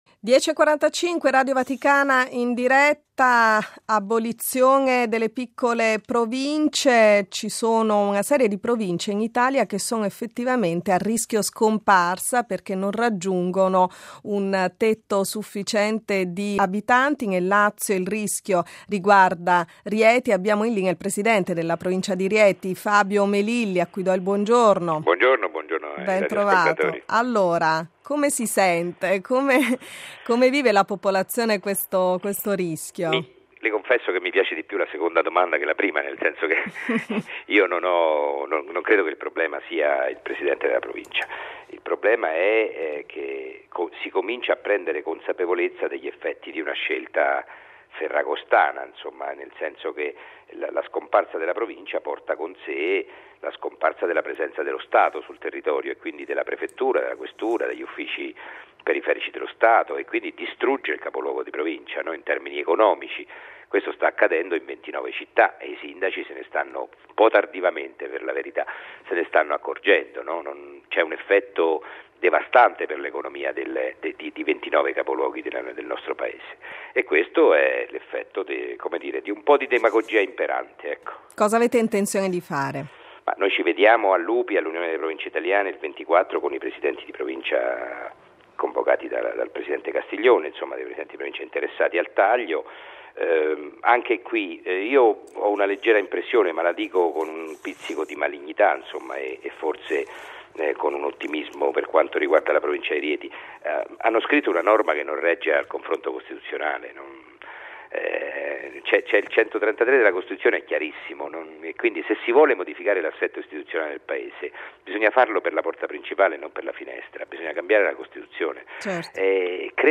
Ai nostri microfoni Fabio Melilli, Presidente della Provincia di Rieti, la realtà del Lazio che rischia di scomparire . L'ipotesi più realistica all'orizzonte - già in gran parte diffusa tra i suoi abitanti - è il passaggio all'Umbria, con l'annessione a Terni e la ricostituzione dell'antica terra di Sabina.